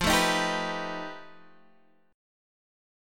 F9b5 chord